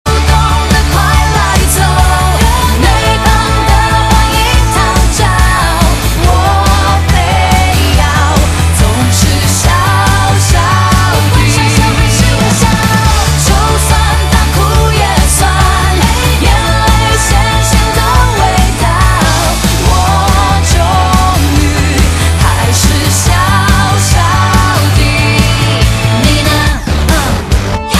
M4R铃声, MP3铃声, 华语歌曲 128 首发日期：2018-05-16 01:56 星期三